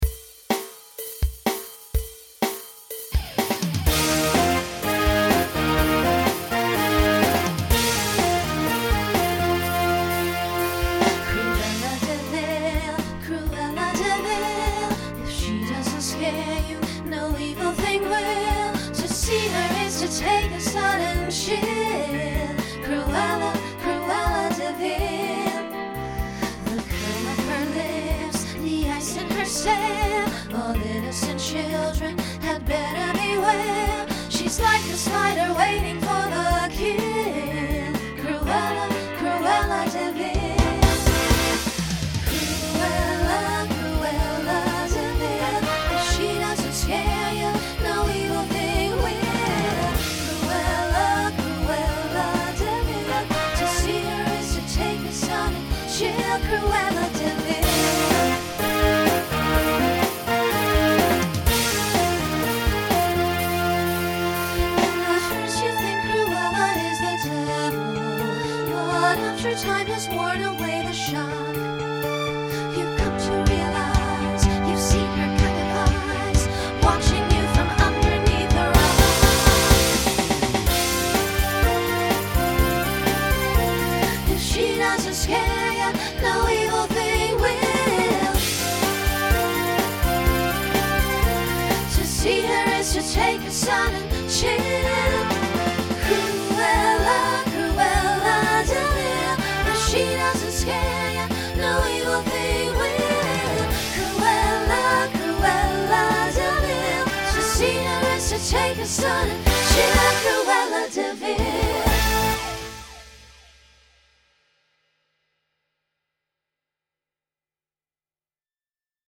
Voicing SSA Instrumental combo Genre Broadway/Film , Rock